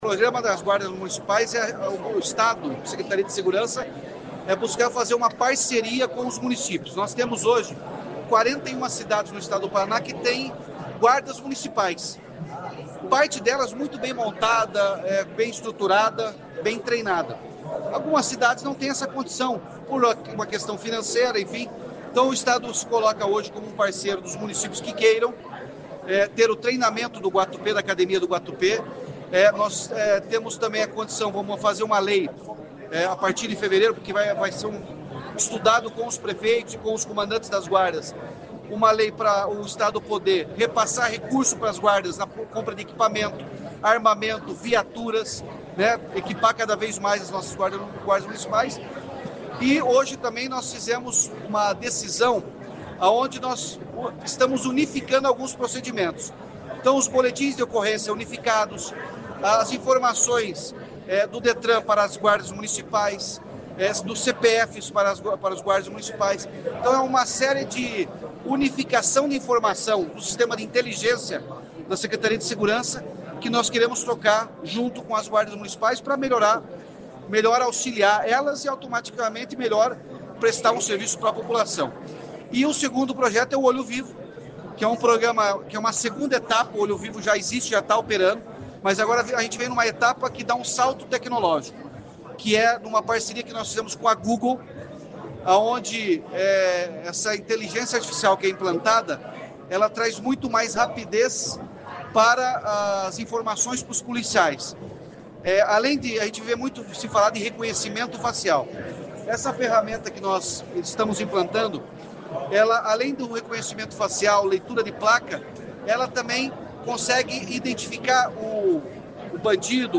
O governador Ratinho Júnior, durante agenda em Maringá, falou sobre dois programas estaduais para melhorar a segurança no Paraná.